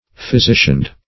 Search Result for " physicianed" : The Collaborative International Dictionary of English v.0.48: Physicianed \Phy*si"cianed\, a. Licensed as a physician.